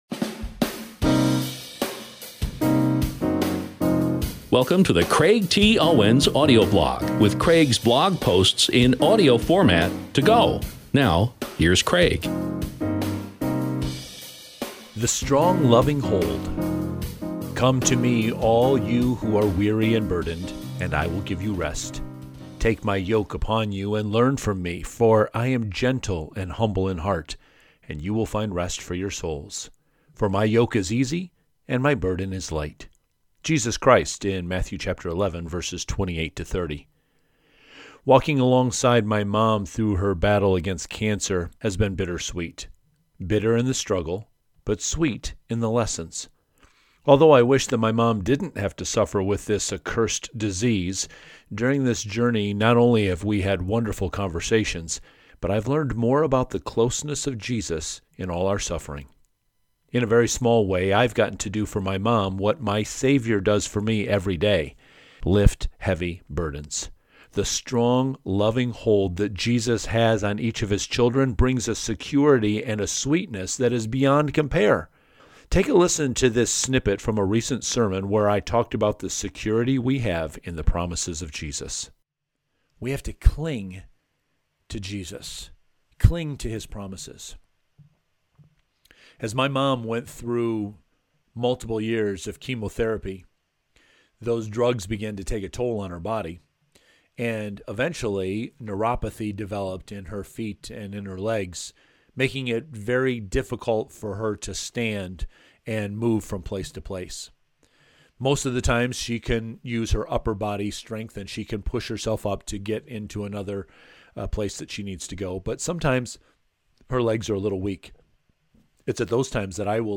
The above snippet is from a message in our series X-ing Out Anxiety, which you can check out by clicking here.